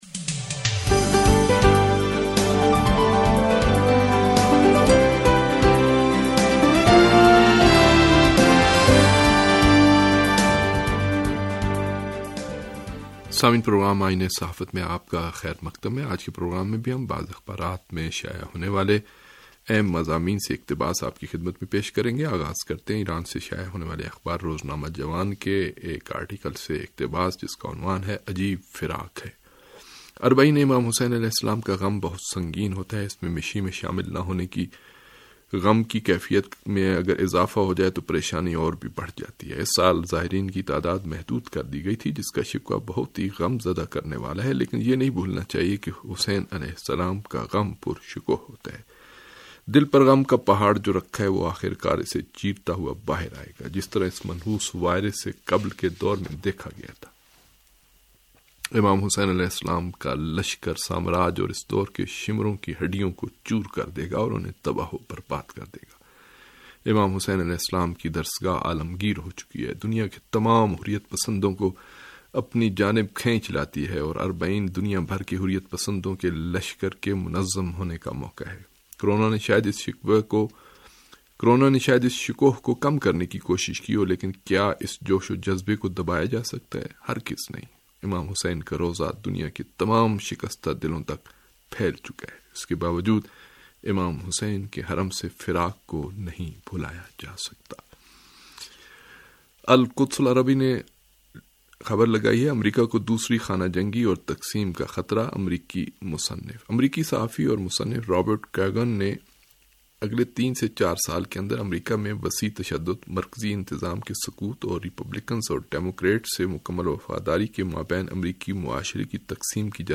ریڈیو تہران کا اخبارات کے جائزے پرمبنی پروگرام آئینہ صحافت